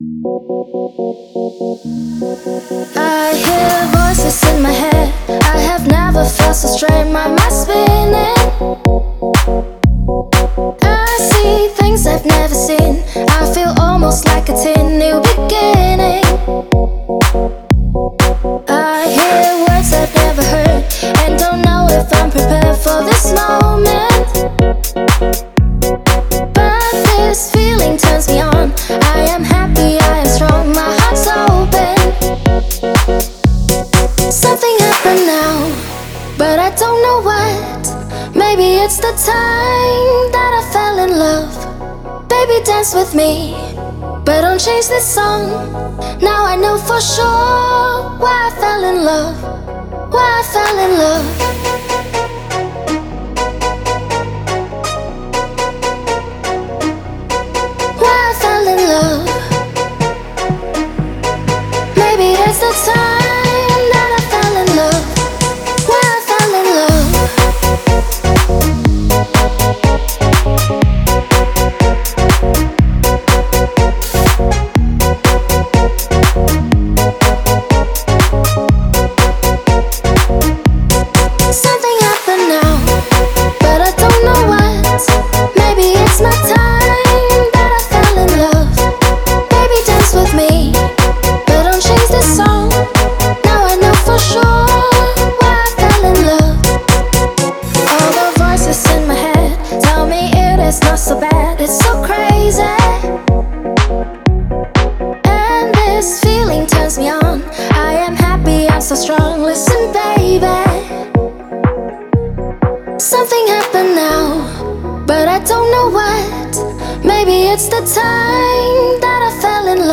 это энергичный трек в жанре EDM